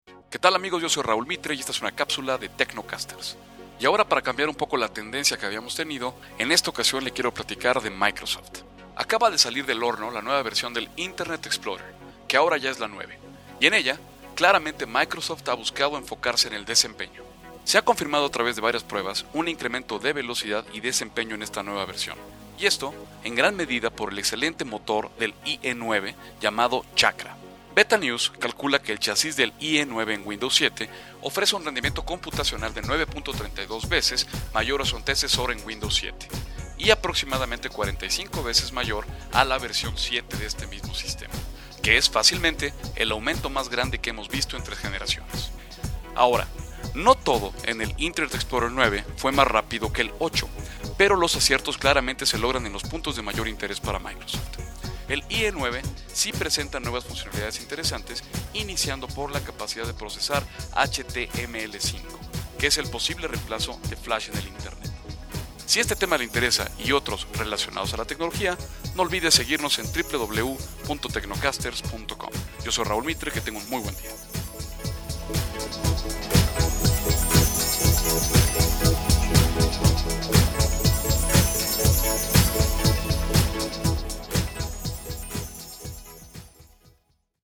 Microsoft Lanza el Internet Explorer 9 - Capsula para Transmision en Radio